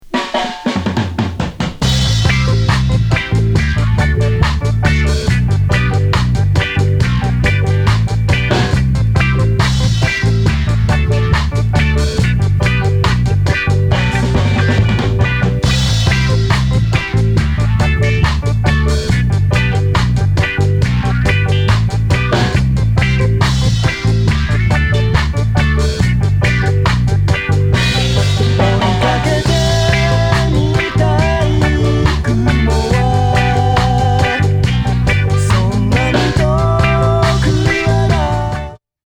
2-TONEライクなハモンド・オルガンの音色もグッと来ます。